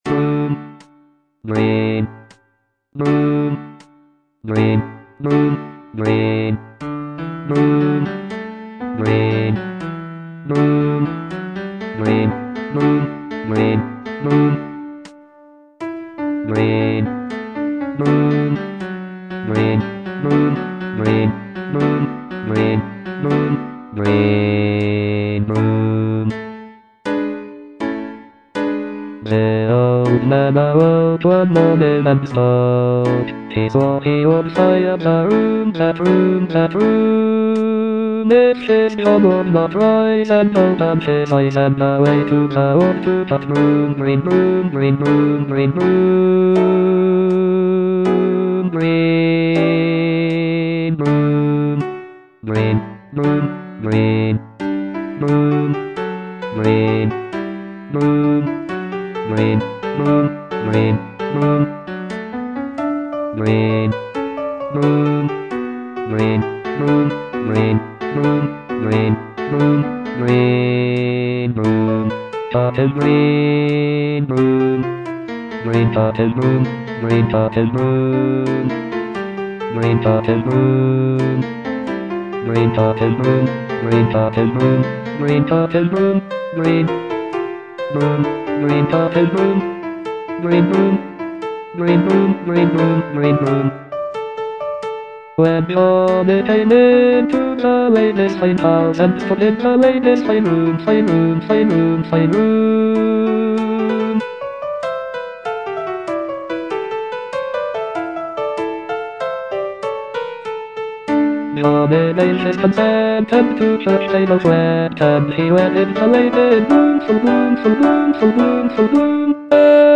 Bass II (Voice with metronome)